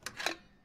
pickupnozzle.ogg